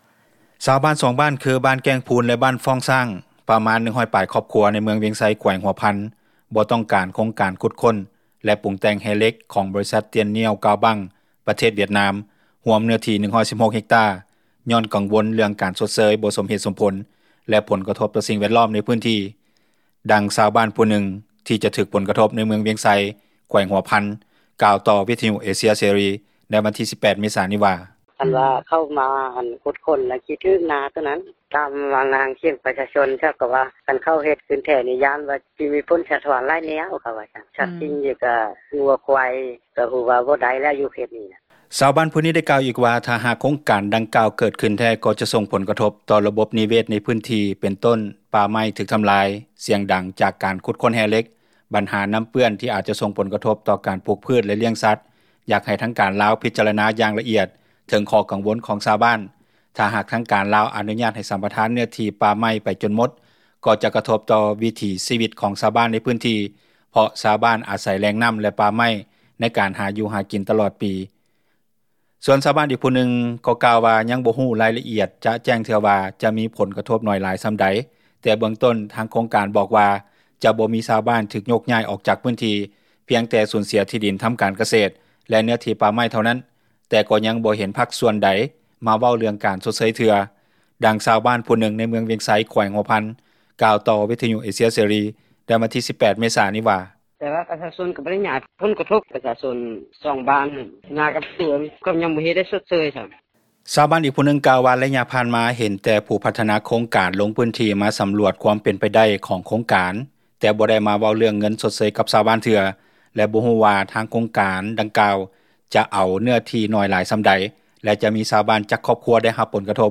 ດ່ັງຊາວບ້ານຜູ້ນຶ່ງທີ່ຈະຖືກຜົລກະທົບ ໃນເມືອງວຽງໄຊ ແຂວງຫົວພັນກ່າວ ຕໍ່ວິທຍຸເອເຊັຽເສຣີ ໃນວັນທີ 18 ເມສານີ້ວ່າ:
ດັ່ງເຈົ້າໜ້າທີ່ຫ້ອງການ ຊັພຍາກອນທັມມະຊາດ ແລະສິ່ງແວດລ້ອມ ເມືອງວຽງໄຊ ແຂວງຫົວພັນ ທ່ານນຶ່ງກ່າວ ຕໍ່ວິທຍຸເອເຊັຽເສຣີ ໃນວັນທີ 18 ເມສານີ້ວ່າ:
ດັ່ງເຈົ້າໜ້າທີ່ຜແນກພລັງງານ ແລະບໍ່ແຮ່ແຂວງຫົວພັນ ທ່ານນຶ່ງກ່າວຕໍ່ວິທຍຸເອເຊັຽເສຣີ ໃນວັນທີ 18 ເມສານີ້ວ່າ: